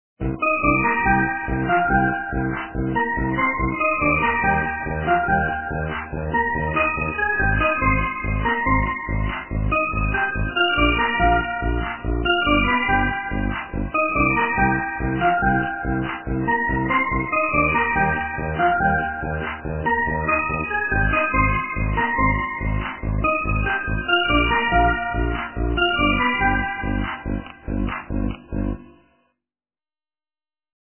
- русская эстрада
полифоническую мелодию